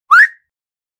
cartoon-metal-whistle-cut-zovcewzt.wav